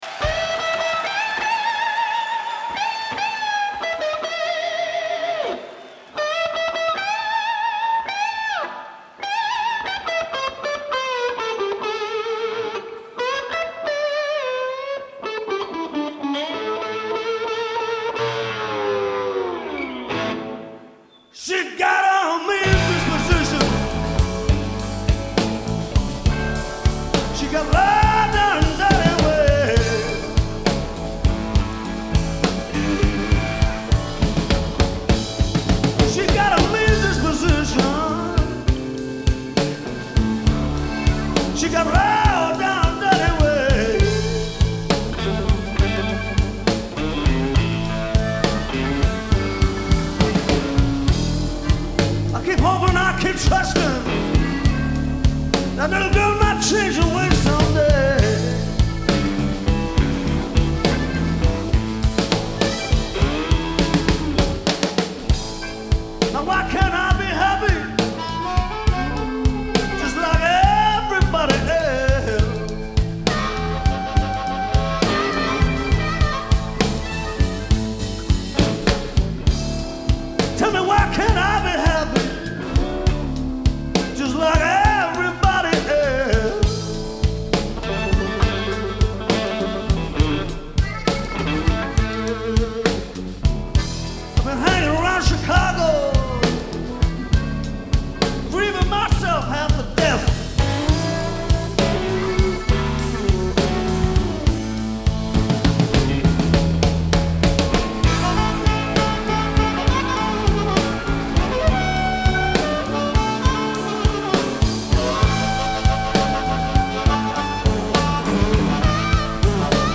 Genre : Blues
Live At The Town & Country Club, London, UK